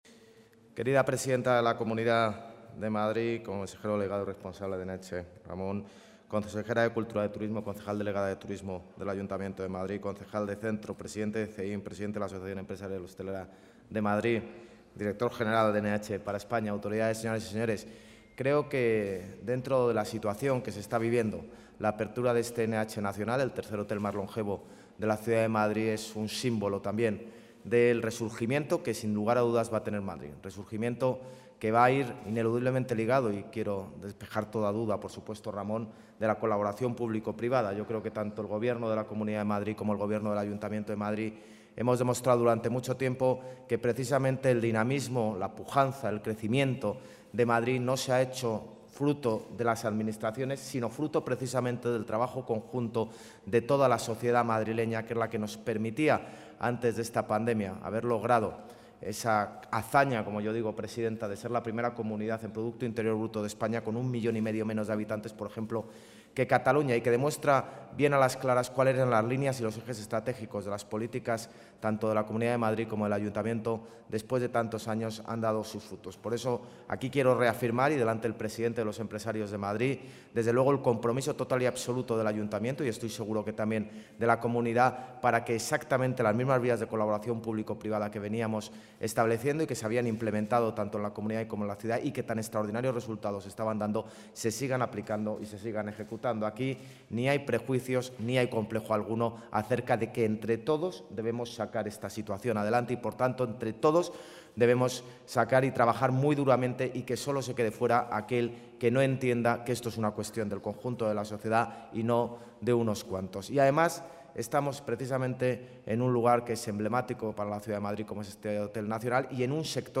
AUDIO-INTERVENCIÓN-ALCALDE-REAPERTURA-HOTEL-NH.mp3